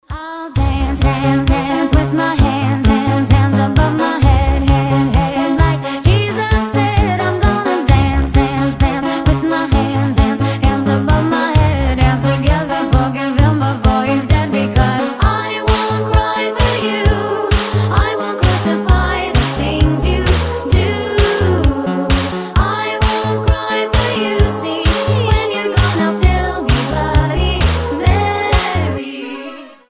• Качество: 320, Stereo
Mashup
ремиксы